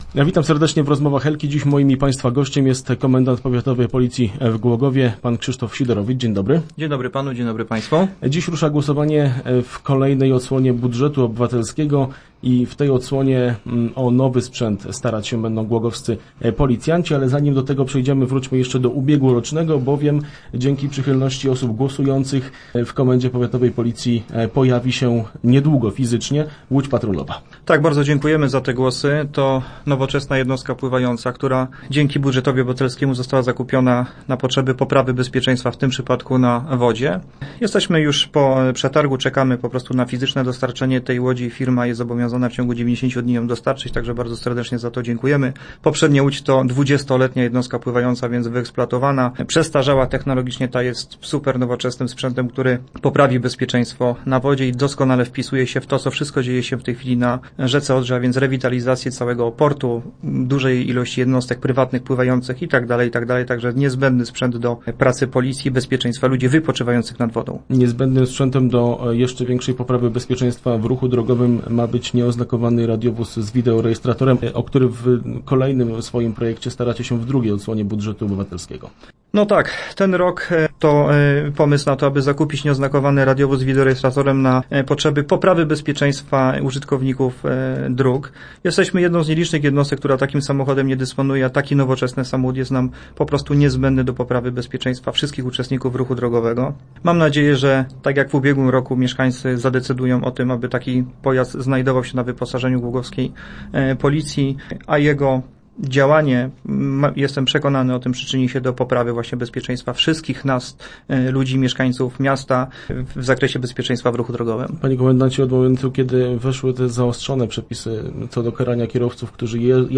Teraz jest szansa, że głogowską policję w ramach budżetu obywatelskiego zasili nieoznakowany radiowóz z wideorejestratorem. Komendant Powiatowy Policji w Głogowie Krzysztof Sidorowicz był gościem poniedziałkowych Rozmów Elki.